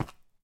sounds / step / stone3.mp3
stone3.mp3